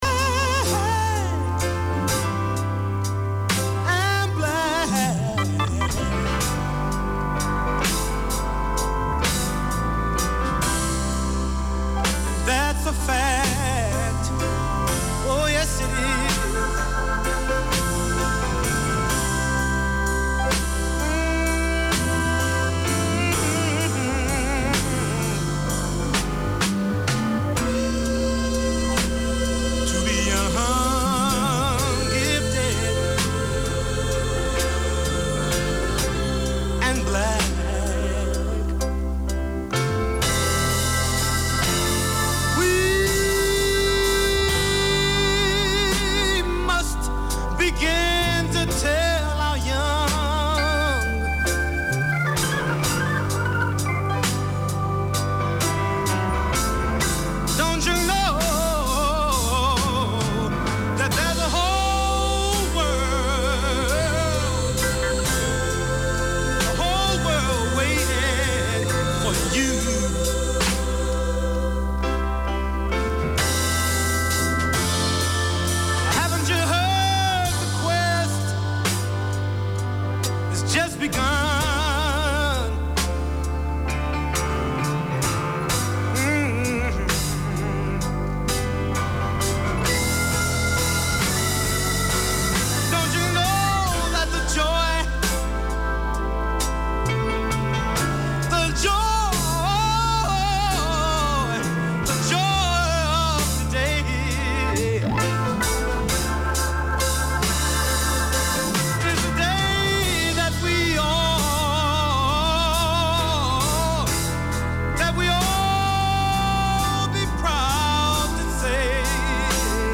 Community Access Radio in your language - available for download five minutes after broadcast.
A programme for Sri Lankan Kiwis in Auckland and beyond, Sriwi Hada offers practical advice on everything from legal issues, immigration, getting around the city, and everything you need to know for surviving and thriving in Aotearoa. All this, and great music!